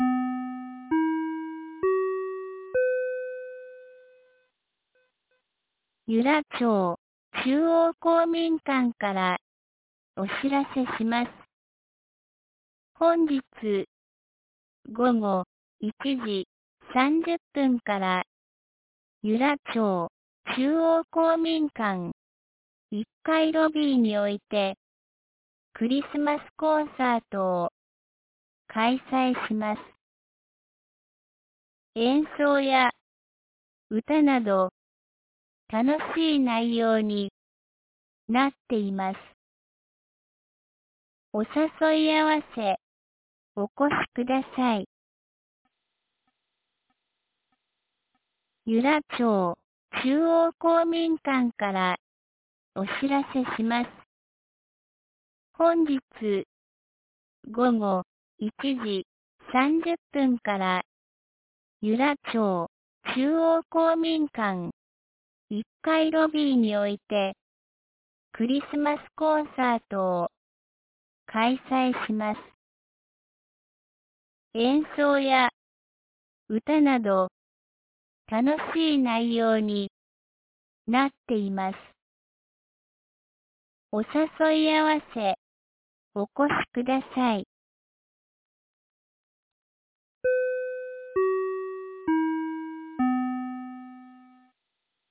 2025年12月13日 07時52分に、由良町から全地区へ放送がありました。